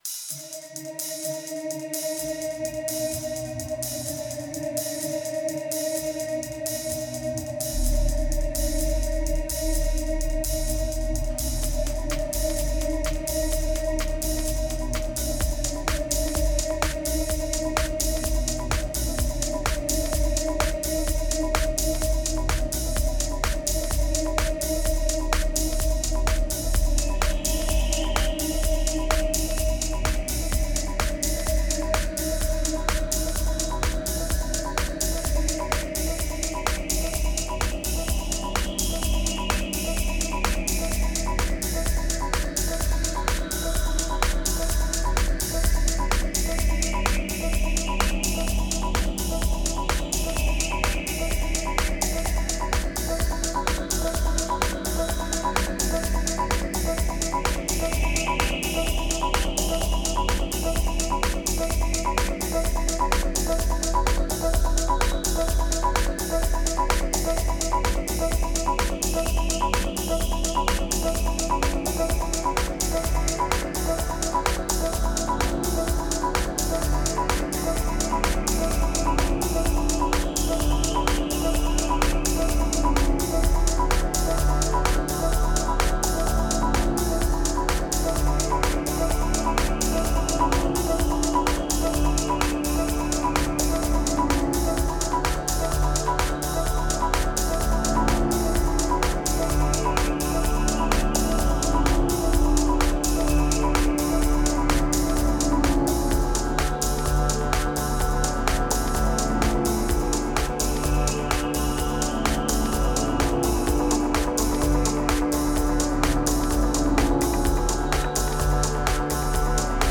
Feels like sunday with a twist.